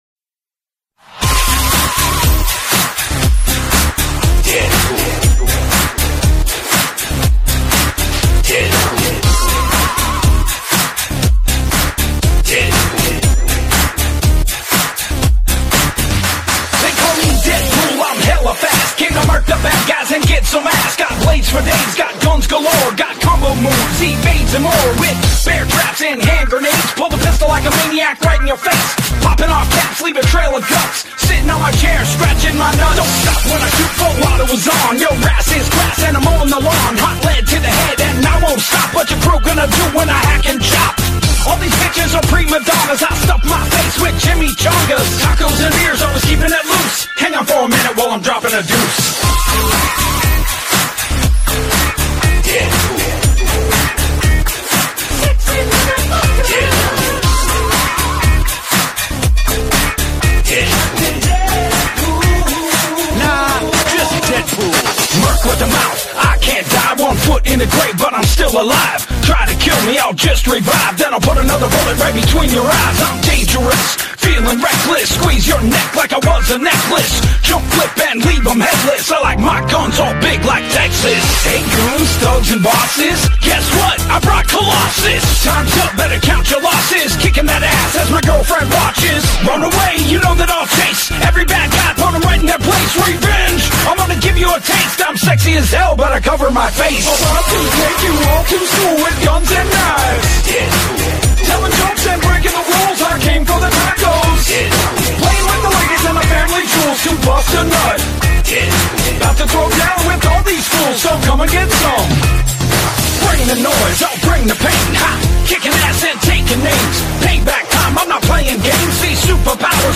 دانلود آهنگ رپ ( داب استپ )